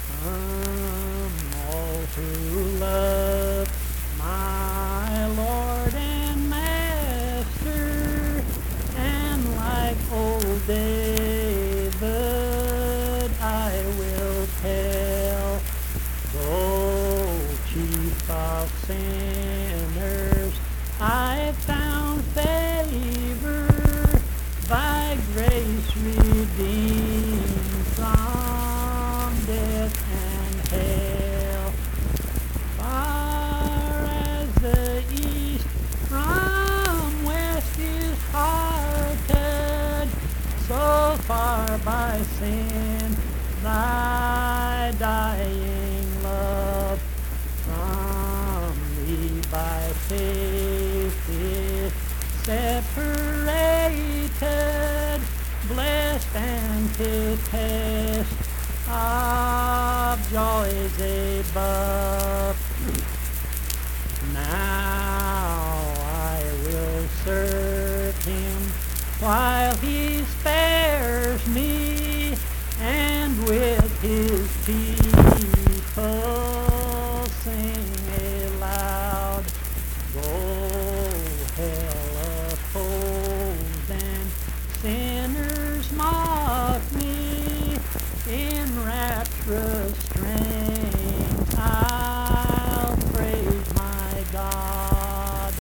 Accompanied (guitar) and unaccompanied vocal music
Verse-refrain 5(2)&R(2). Performed in Mount Harmony, Marion County, WV.
Hymns and Spiritual Music
Voice (sung)